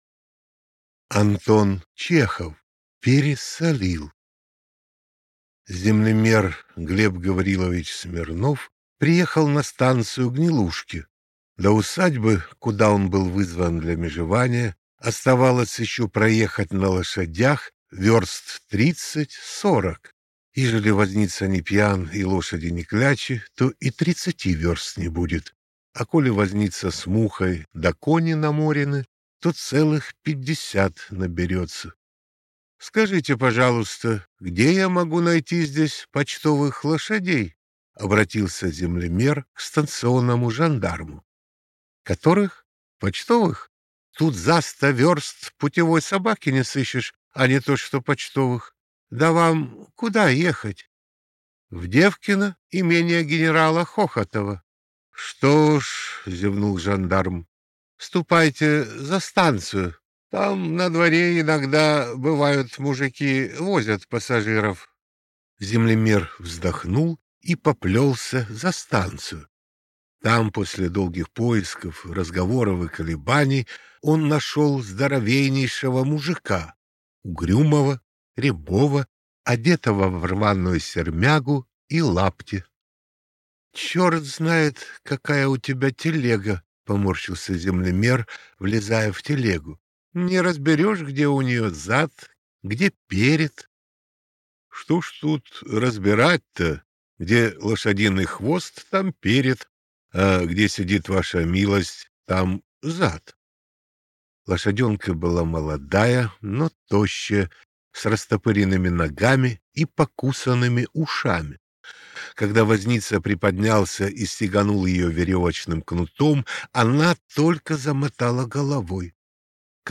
Аудиокнига Пересолил | Библиотека аудиокниг